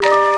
loop (ROLI SOUND).wav